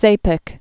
(sāpĭk)